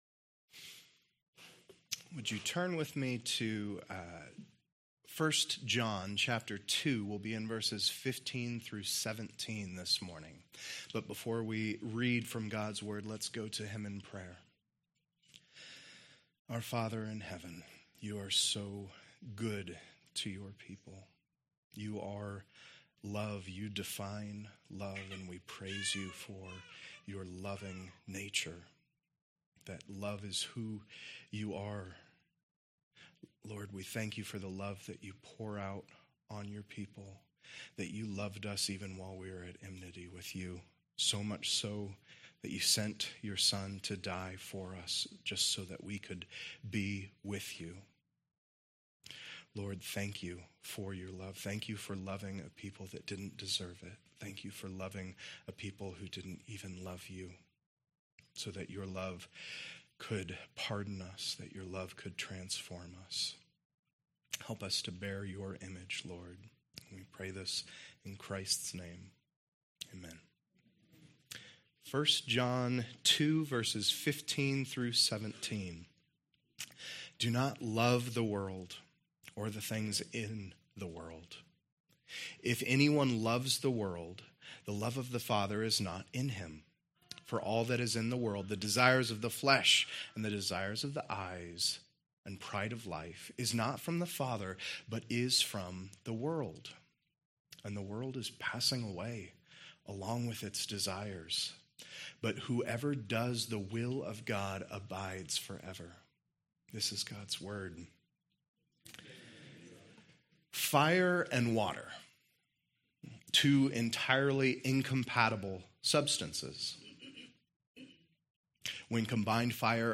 Genesis 3:1-7 Sermon text: 1 John 2:15-17 Pastor